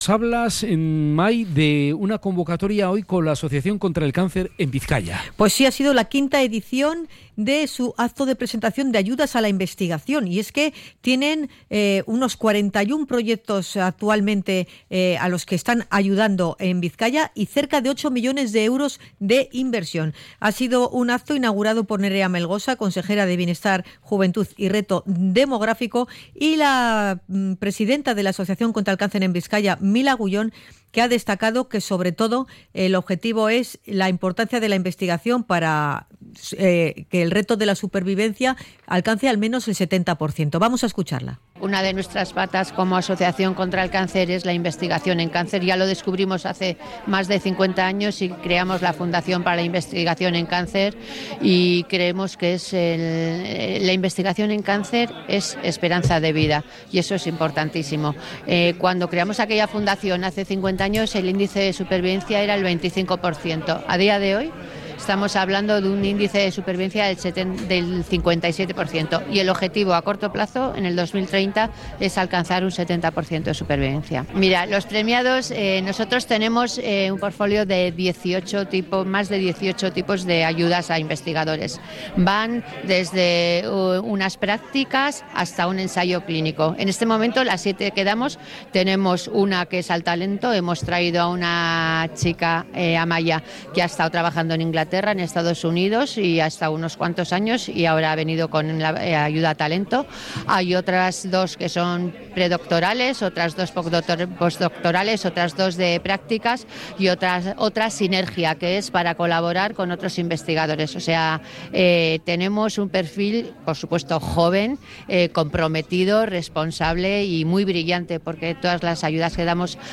Hemos estado en la gala de presentación de las nuevas ayudas